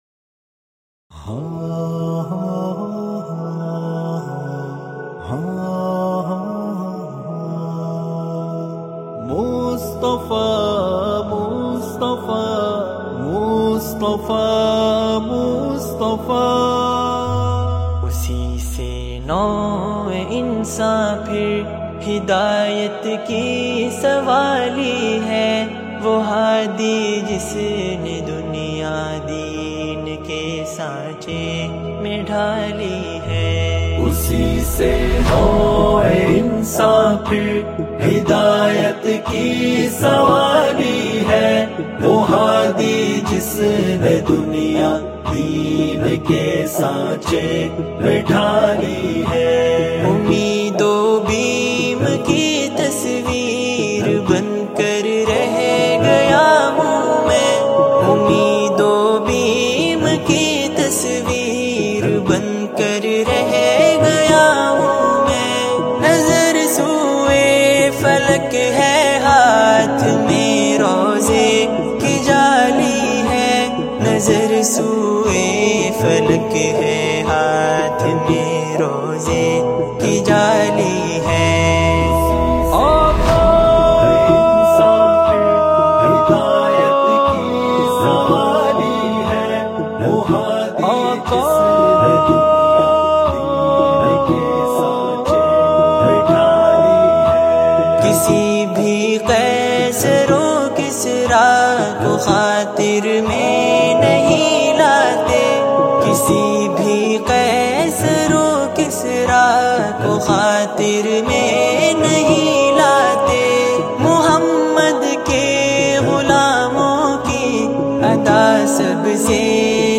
Naats